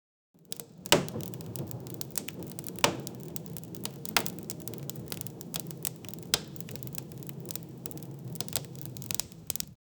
fireplace in the background rain on window
fireplace-in-the-backgrou-57bmqyke.wav